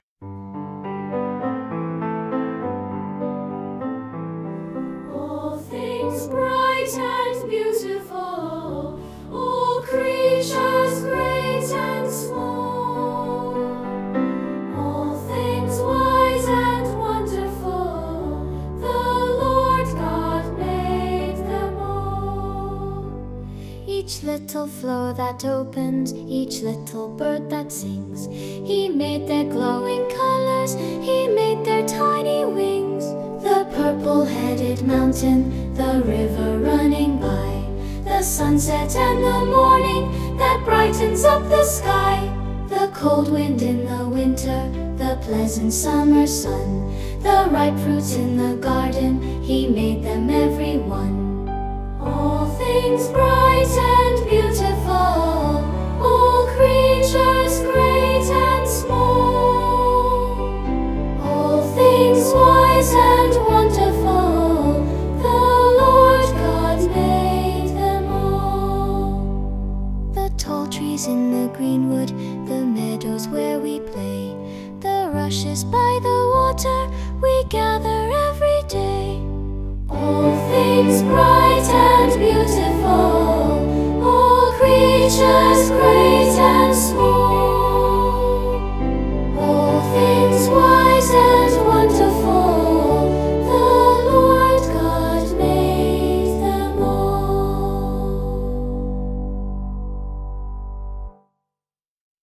All Things Bright and Beautiful (by K.D. Christensen -- Primary Children/Primary Solo)